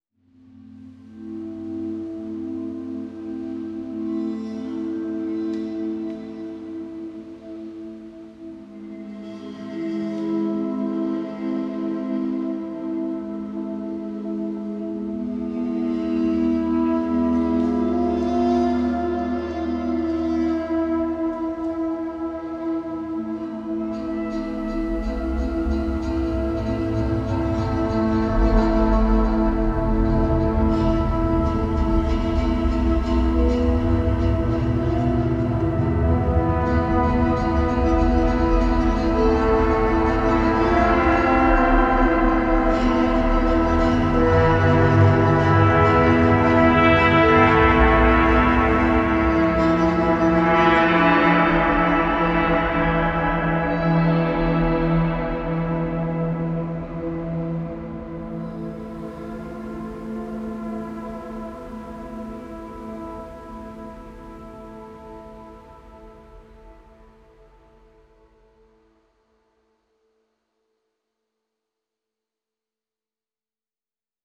• Жанр: Электро